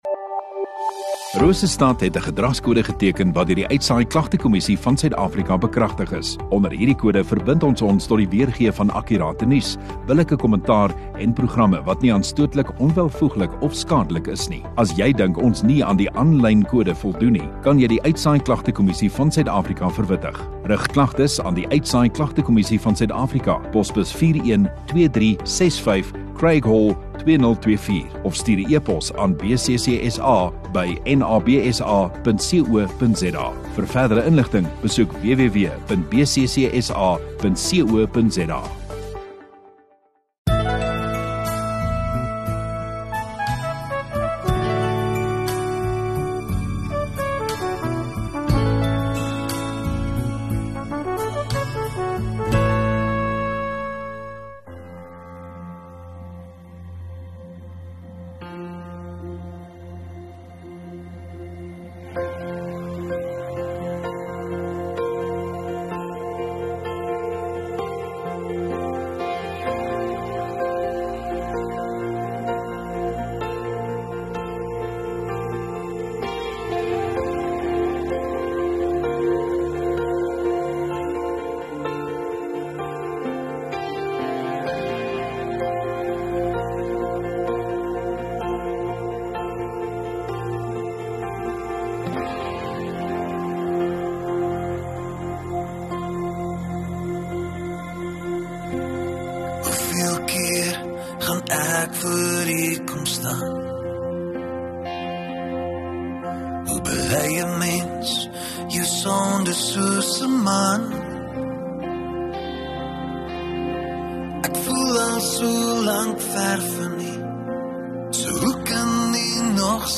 1 Jun Sondagaand Erediens